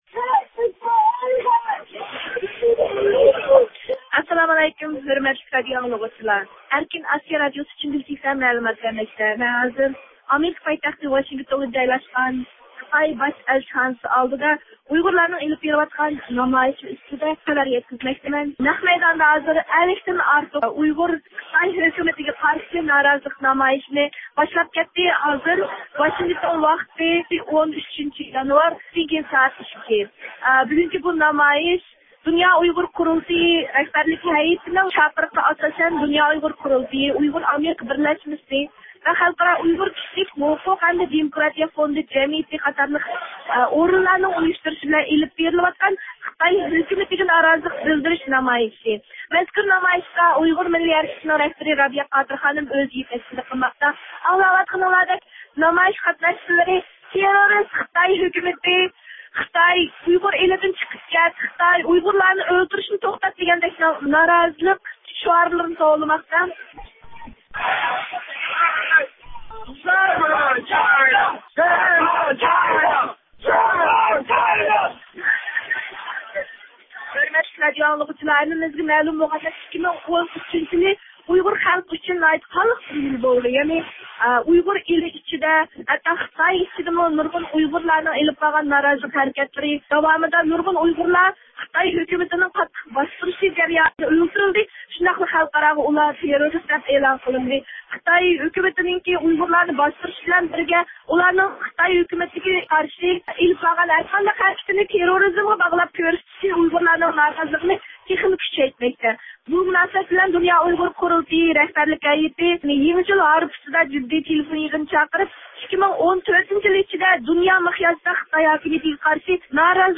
بۇ مۇناسىۋەت بىلەن، بۈگۈن ئامېرىكا ئۇيغۇر بىرلەشمىسىنىڭ ئۇيۇشتۇرۇشىدا ئامېرىكا پايتەختى ۋاشىنگتوندىكى خىتاي ئەلچىخانىسى ئالدىدا نامايىش ئۆتكۈزۈلدى.